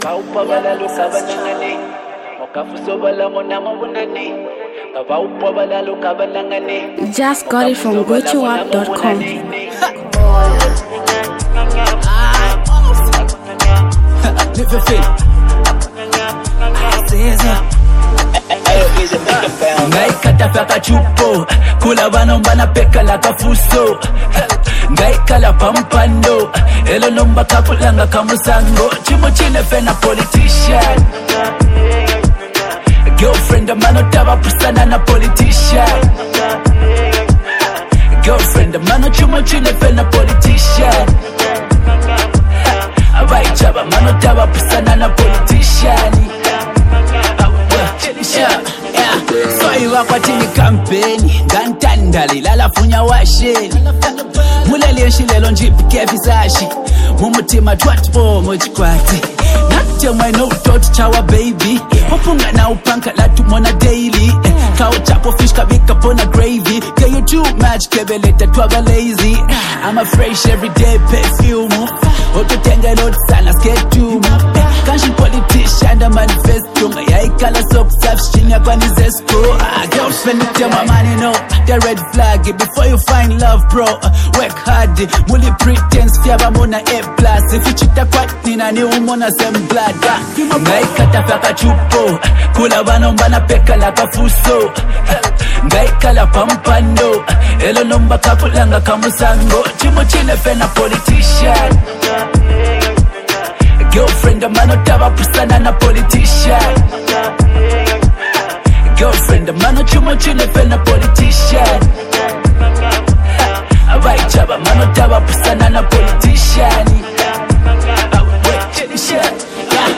Zambian Mp3 Music
rap
street hit anthem